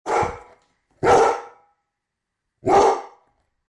Throaty Dog Bark Bouton sonore